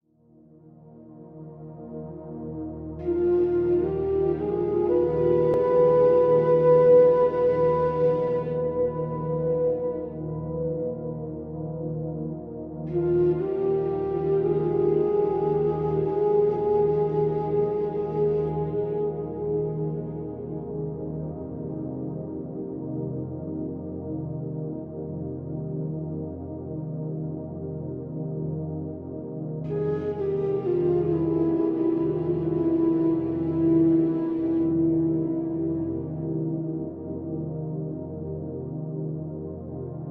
埃及古风.mp3